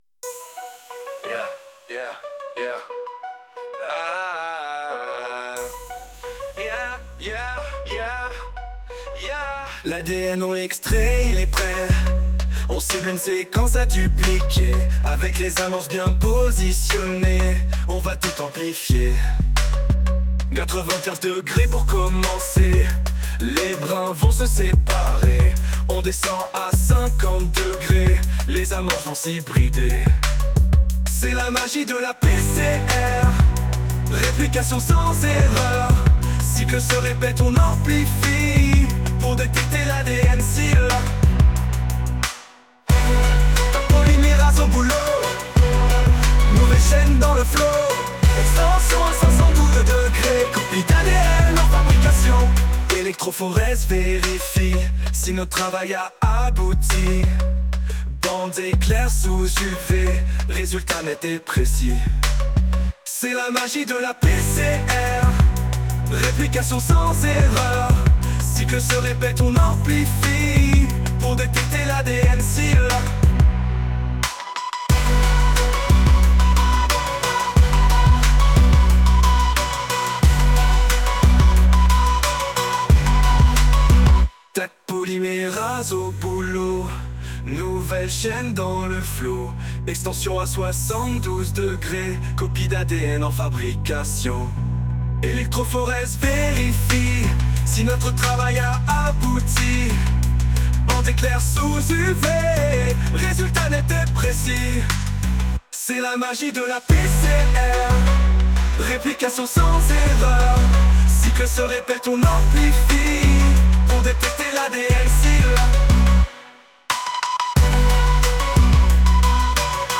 • Les paroles sont utilisées dans l’outil SUNO AI qui va générer une chanson dans le style choisi à partir des paroles.
en style Rap